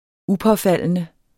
Udtale [ ˈupʌˌfalˀənə ]